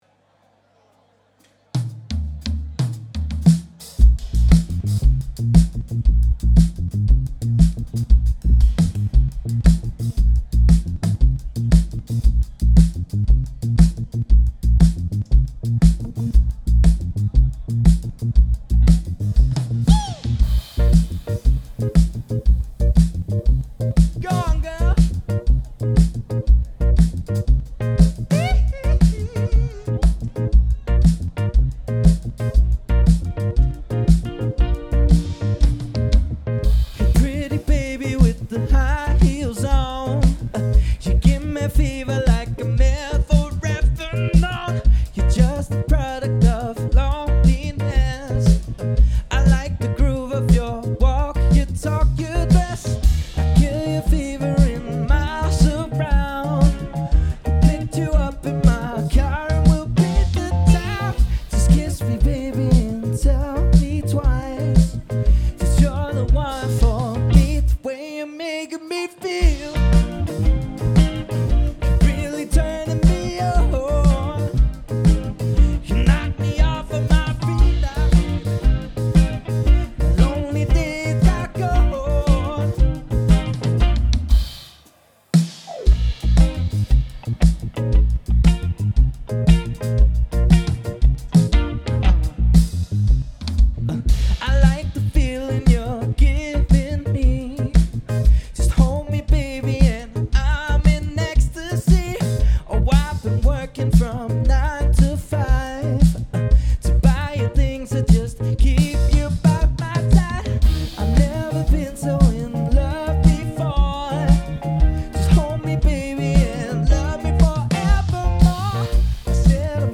• Coverband
• Soul/Funk/Groove